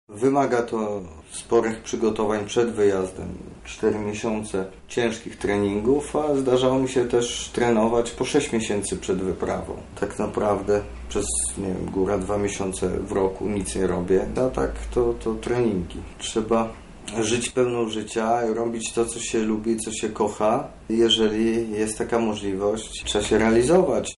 Przygotowania do dwumiesięcznej wyprawy trwały co najmniej dwa razy dłużej. Mówi himalaista.